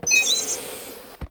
airprox.ogg